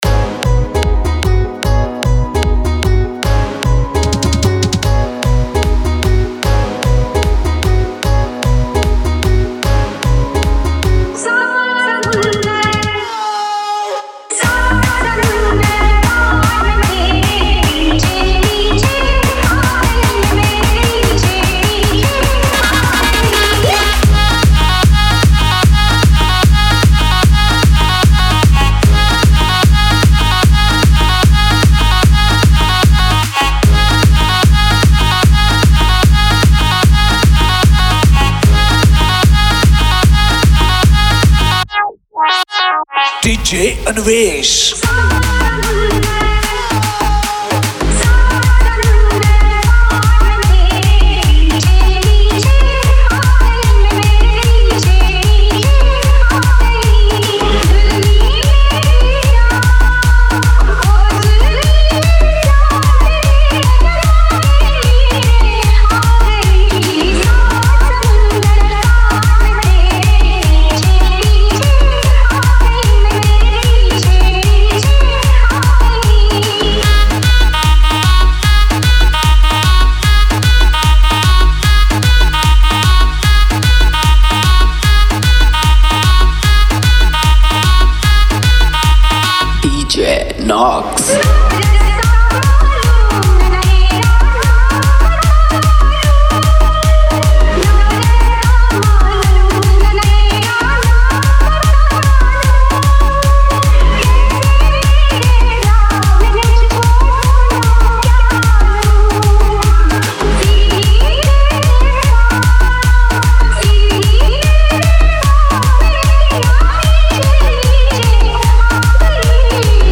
Ganesh Puja Special Dj 2023 Songs Download